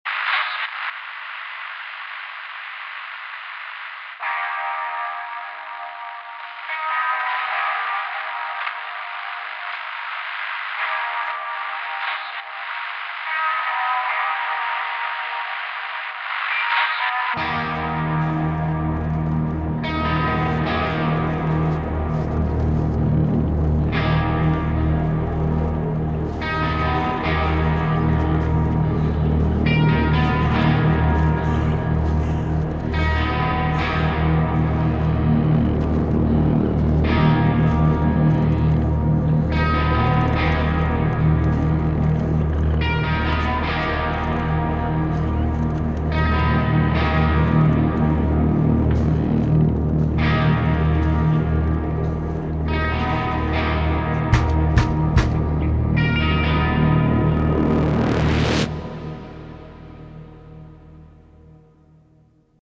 Category: Sleaze Glam